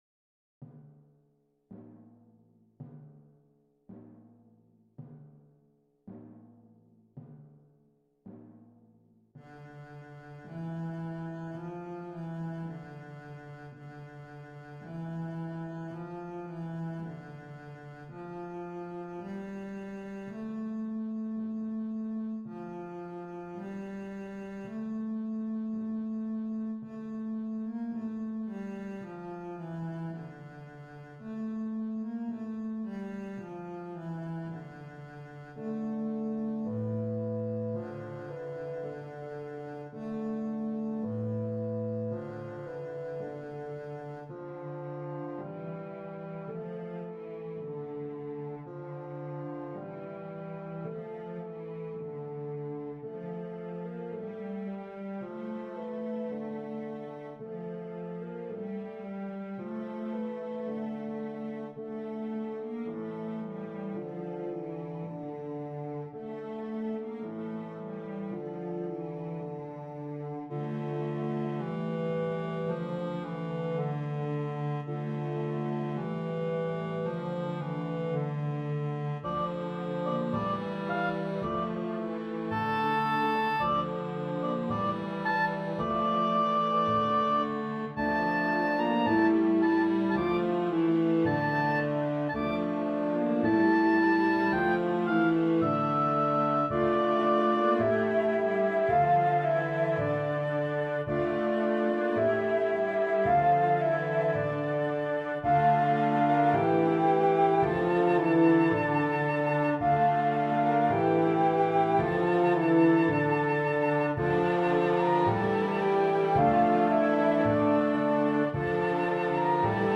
(Extract of 3rd movement: Details, MIDI or